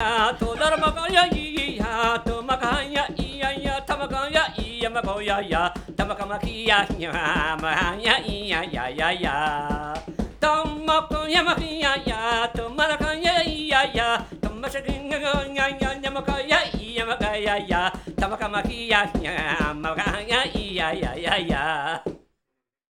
gtr